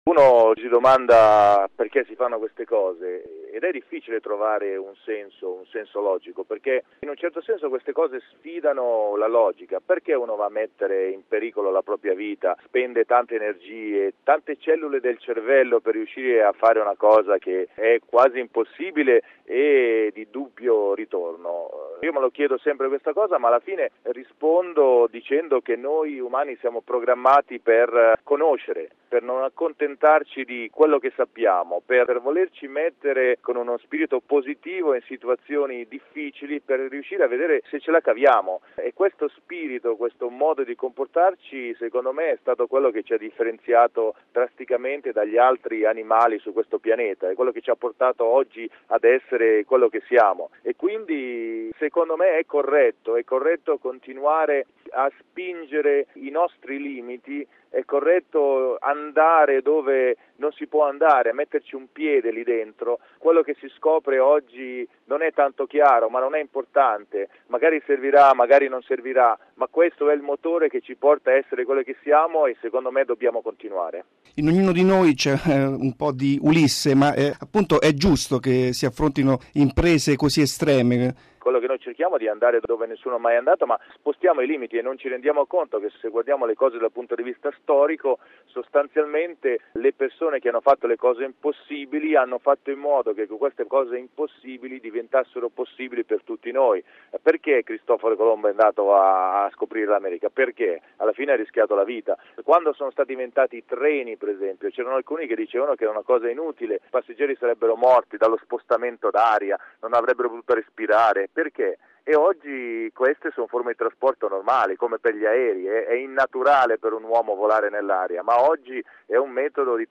Baumgartner supera il muro del suono in caduta libera: intervista con l'astronauta Paolo Nespoli